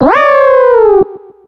Cri de Mamanbo dans Pokémon X et Y.